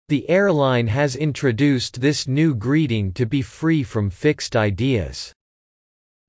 ディクテーション第1問
【ややスロー・スピード】